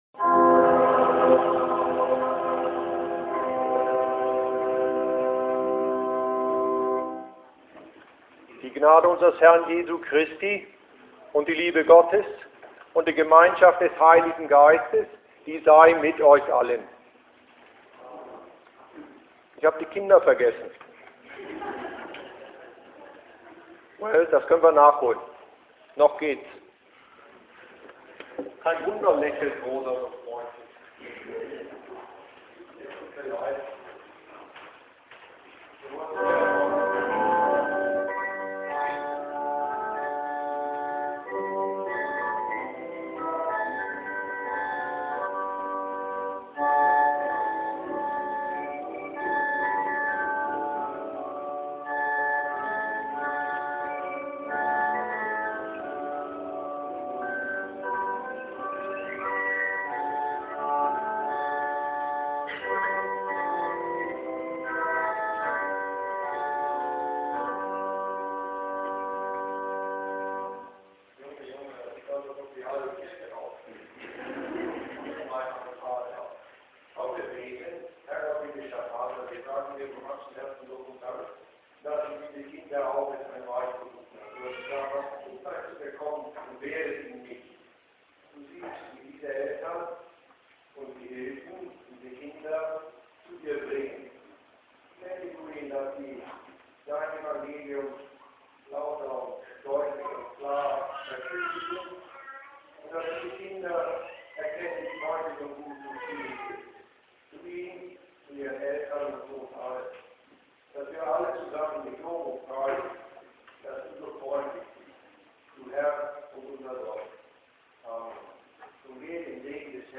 Predigt zu Mt.6,1-4: Wer ist denn mein Bruder?